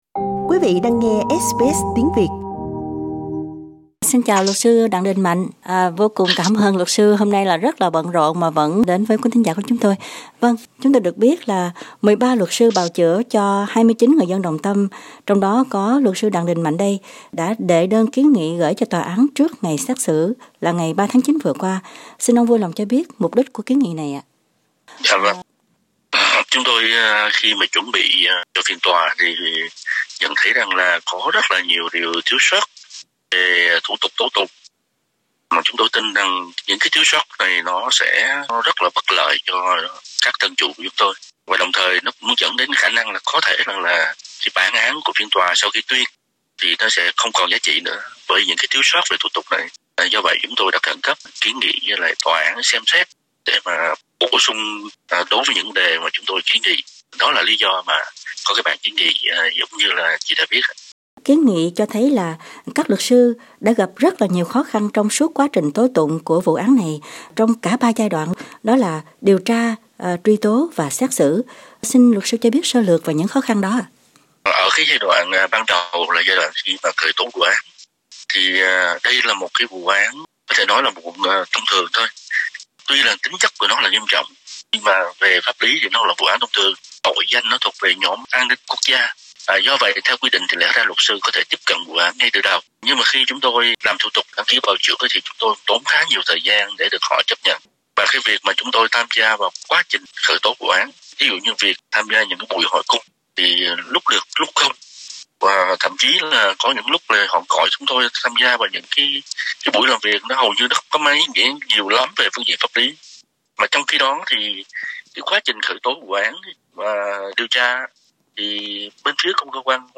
chúng tôi đã có cuộc phỏng vấn ngắn (phần âm thanh đầu trang)